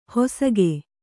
♪ hosage